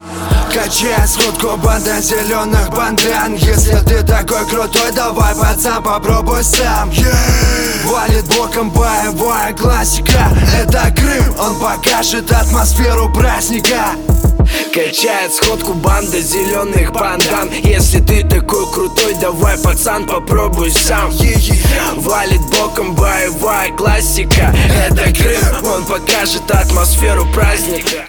• Качество: 128, Stereo
громкие
русский рэп
качающие
Bass